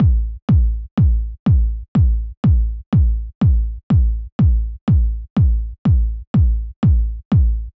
08 Kick.wav